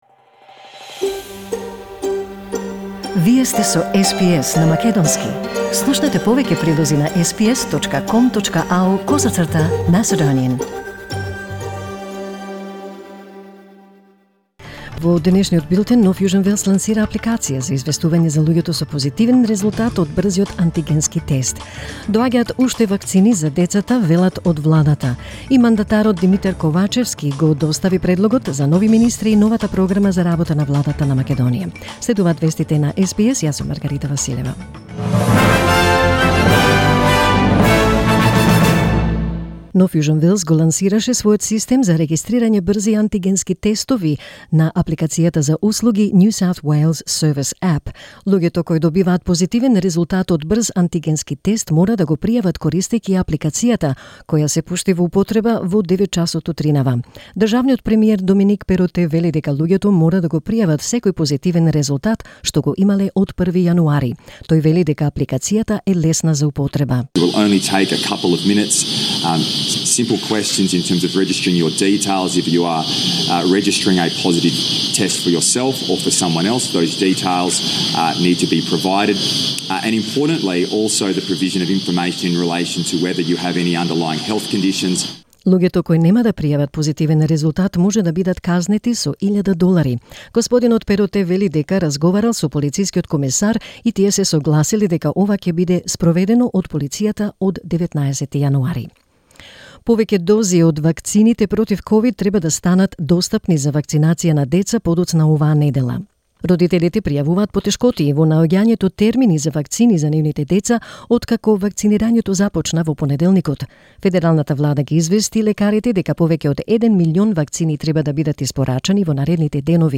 SBS News in Macedonian 12 January 2022